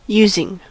using: Wikimedia Commons US English Pronunciations
En-us-using.WAV